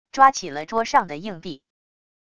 抓起了桌上的硬币wav下载